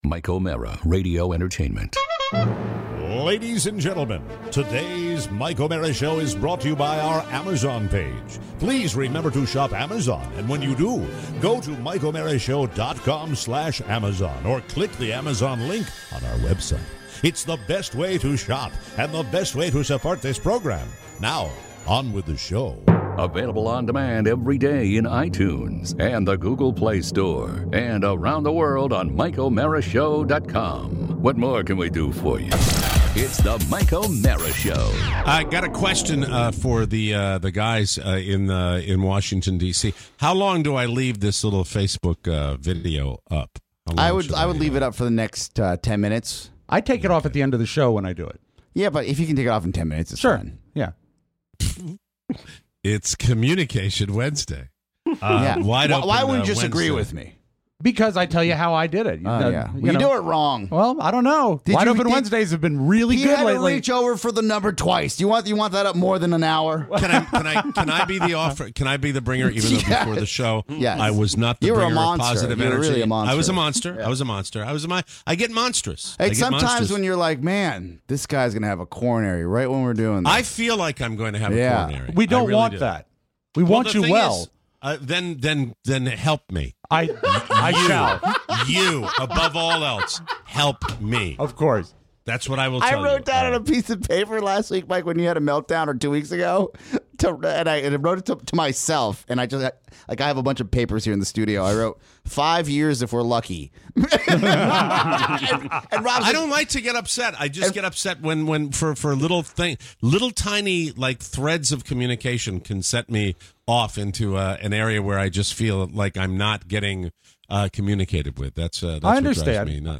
Your calls for Wide Open Wednesday!